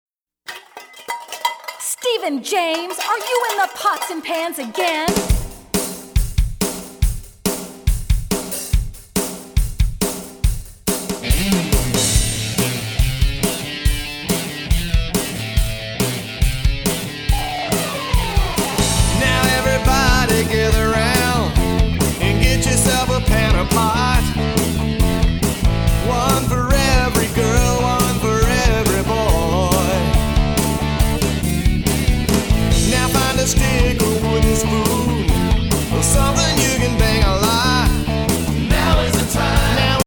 Filled from beginning to end with rockin’ movement tunes